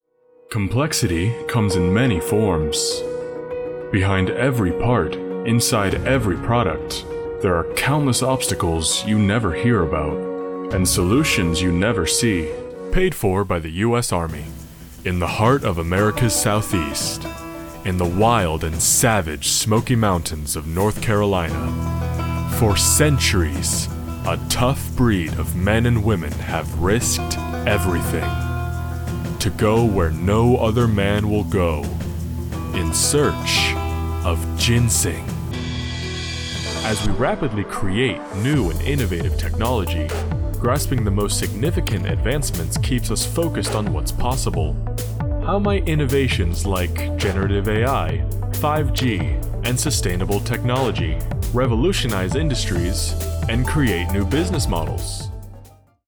An American young adult voice actor with a deep voice specializing in narration
Montage
Standard North American
Young Adult